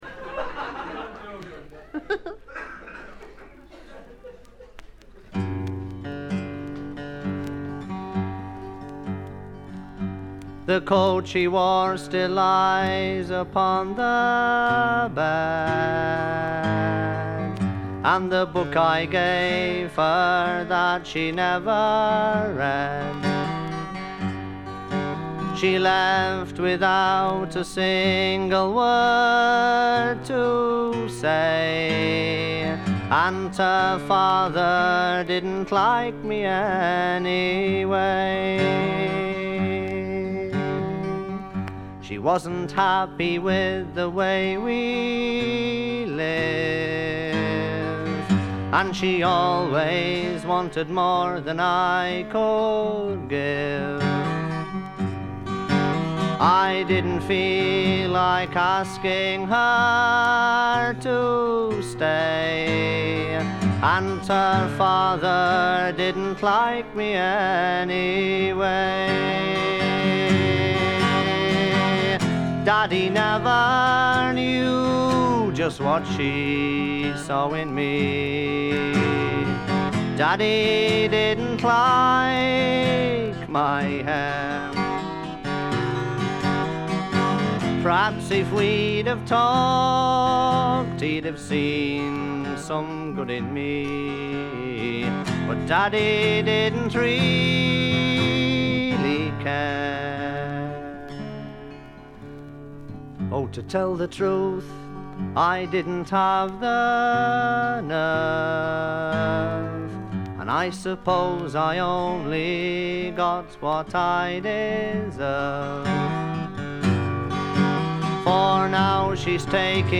バックグラウンドノイズ、チリプチはそこそこ出ますが鑑賞を妨げるほどのノイズはありません。
自身のギターの弾き語りで全13曲。
試聴曲は現品からの取り込み音源です。
guitar, vocals